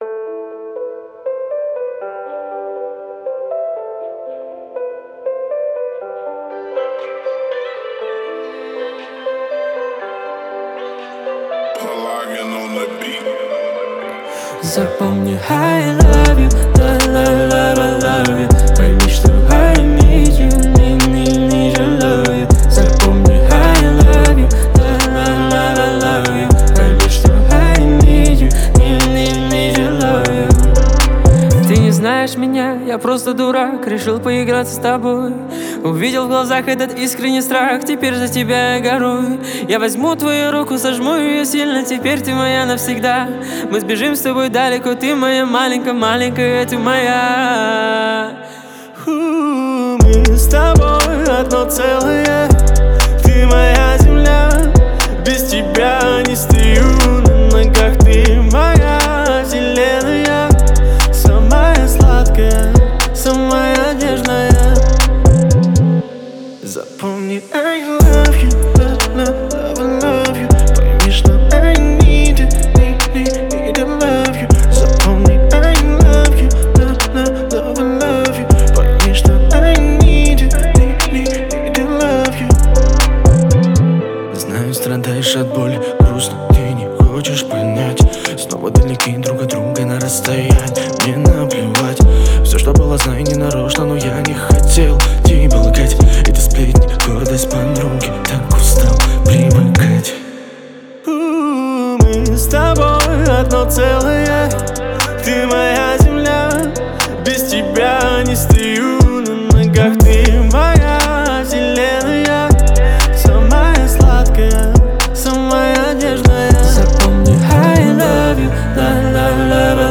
это трек в жанре поп с элементами R&B